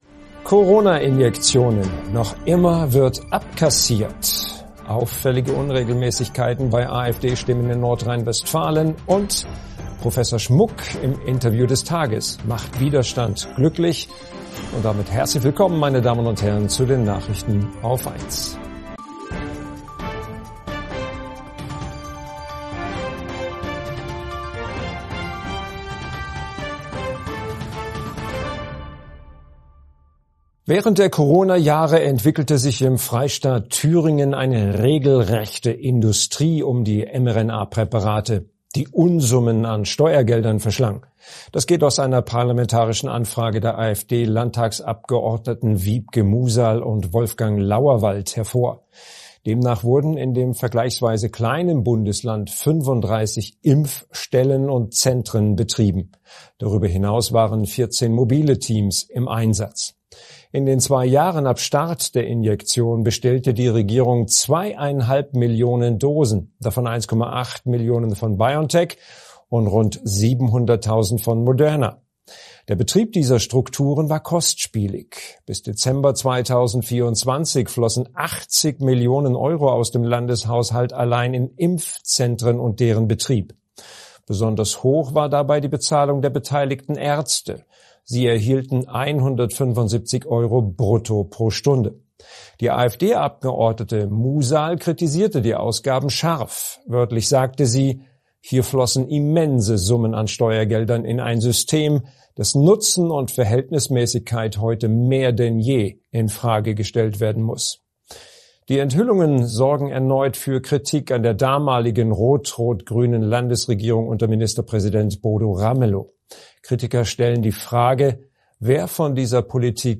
Nachrichten AUF1 vom 17. September 2025 ~ AUF1 Podcast